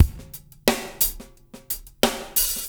SMP DRMWET-R.wav